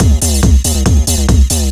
DS 140-BPM B2.wav